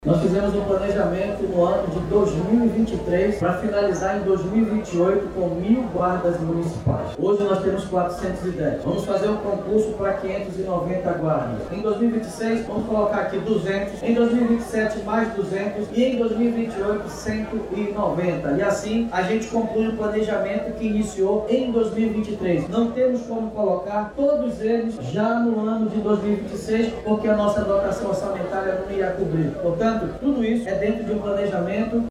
Ainda segundo o prefeito, até 2028 o efetivo da Guarda Municipal vai aumentar de forma planejada.